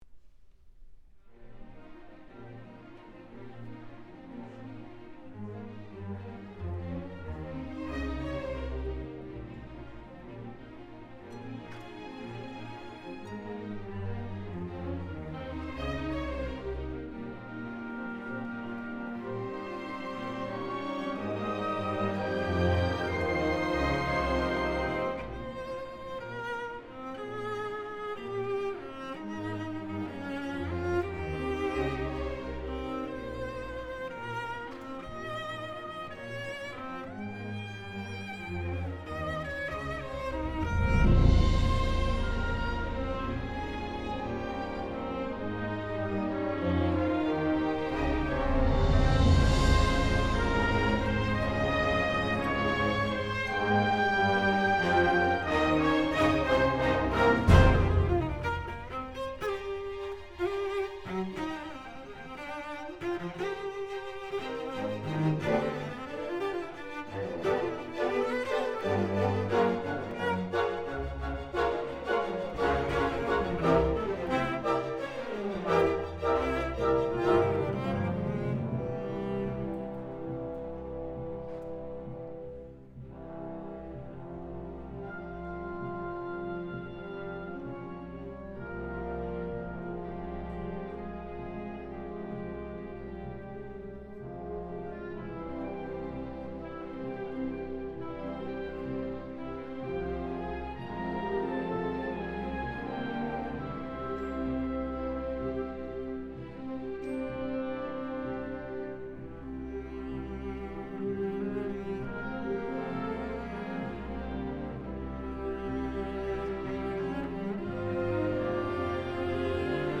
a tone poem for Cello & Orchestra